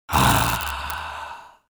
Sigh Male 02
Sigh Male 02.wav